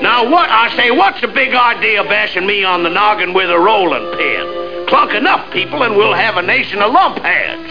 CLUNK.mp3